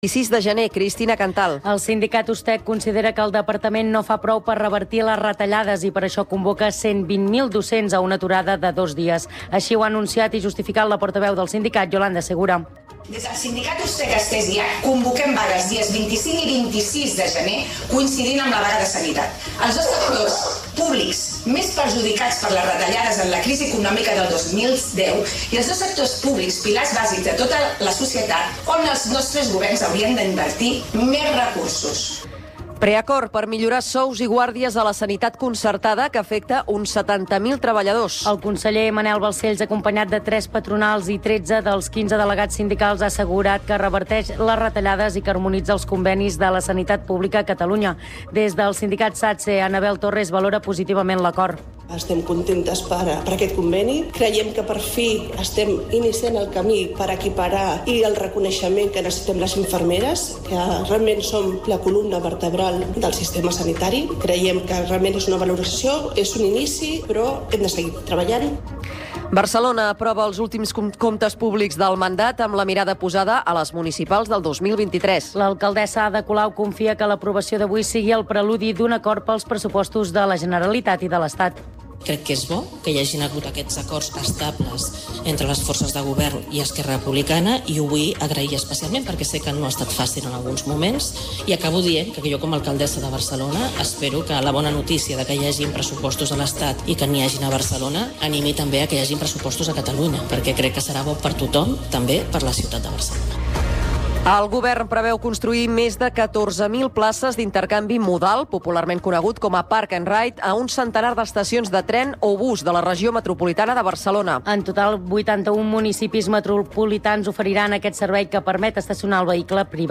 Informatiu en xarxa que fa difusió nacional dels fets locals i ofereix la visió local dels fets nacionals.